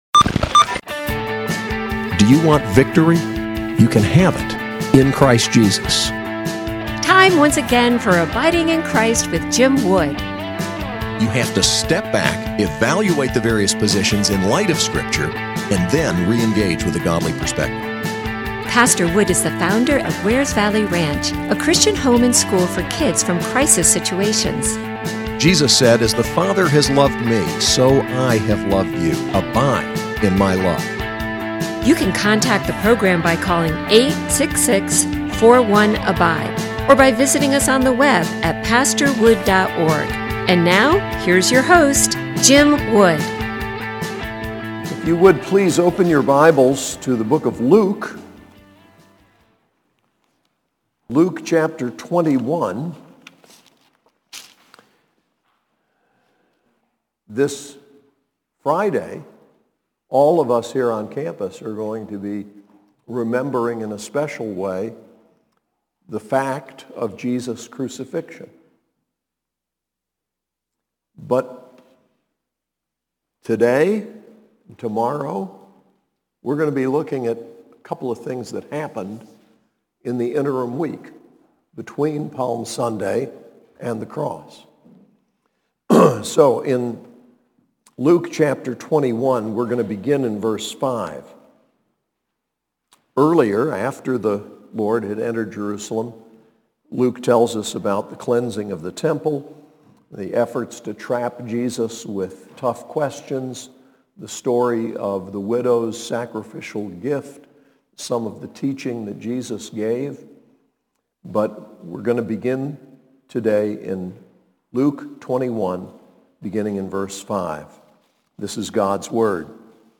SAS Chapel: Luke 21:5-38
Preacher